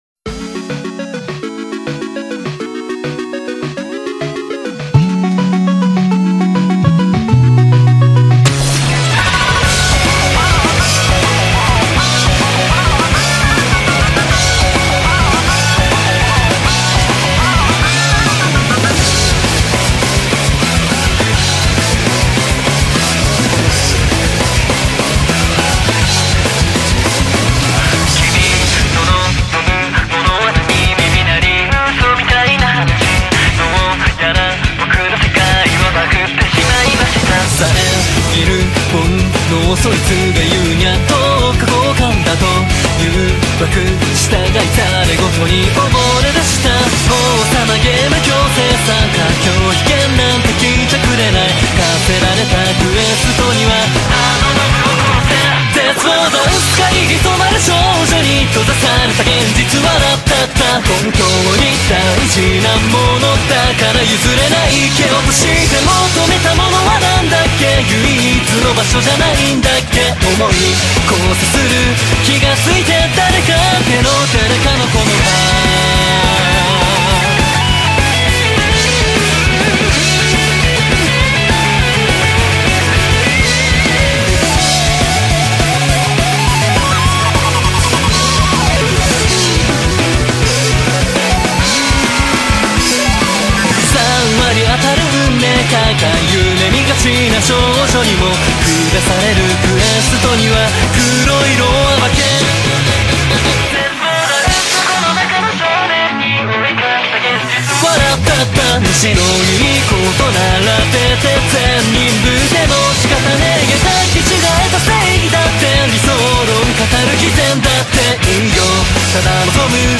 BPM103-205
MP3 QualityMusic Cut